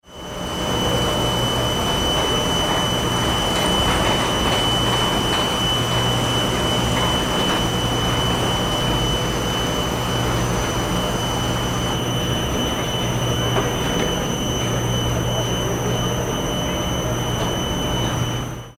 Ferris Wheel Turning Sound: A Carnival Atmosphere
Description: Ferris wheel turning sound at amusement park. Creaking sound of a giant Ferris wheel in an amusement park. The wheel turns slowly with a distinct squeaking noise, perfect for carnival, fairground, and theme park ambience Listen and download in MP3 format.
Genres: Sound Effects
Ferris-wheel-turning-sound-at-amusement-park.mp3